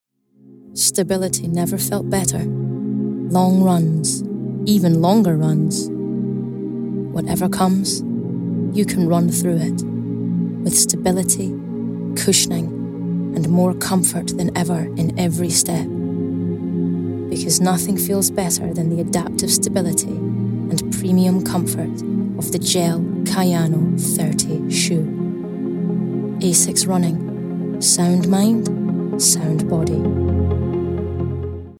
Scottish
Female
Friendly
Warm